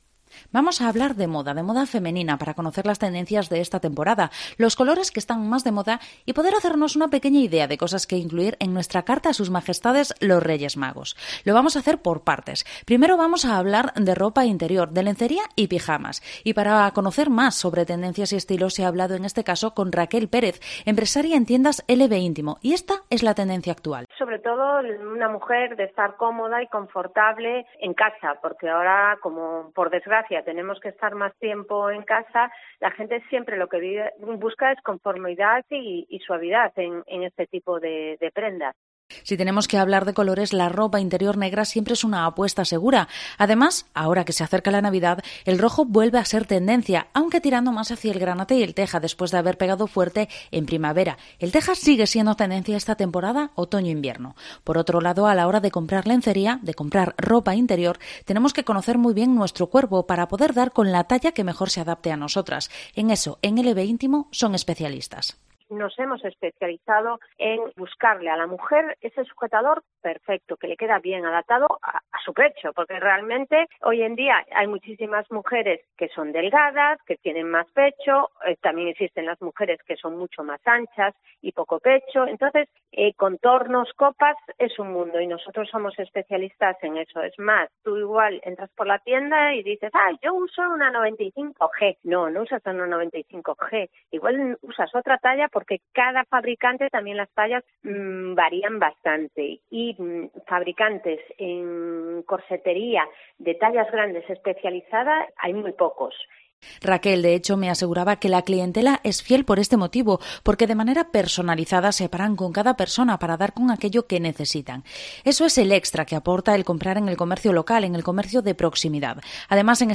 ¿Tendecias es moda femenina? Hablamos con dos profesionales del sector